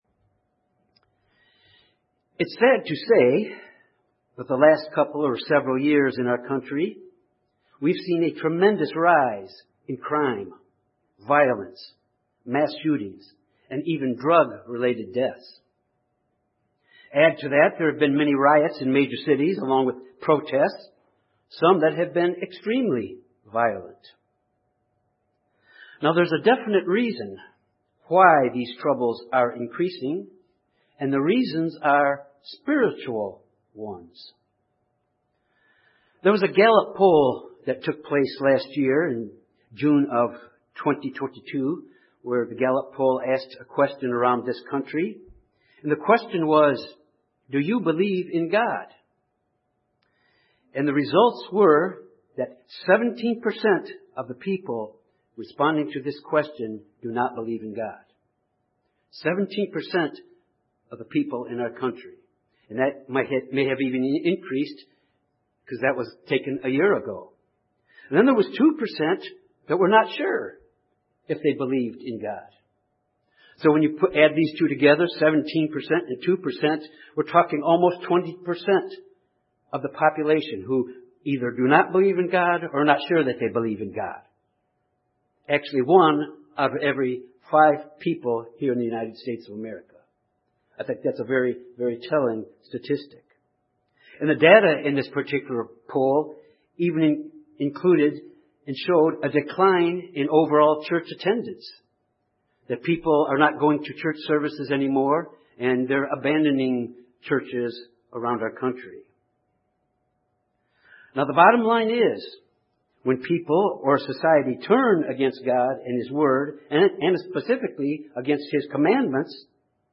When people turn away from God, they suffer the consequences. This sermon examines the importance of God in all aspects of our lives.